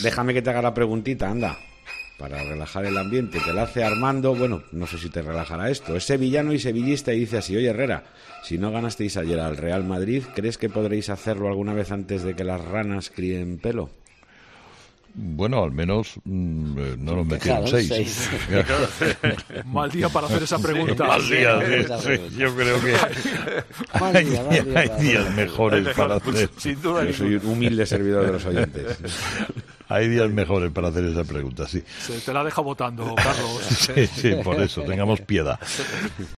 La reacción de Herrera cuando un oyente le pregunta por el Betis-Real Madrid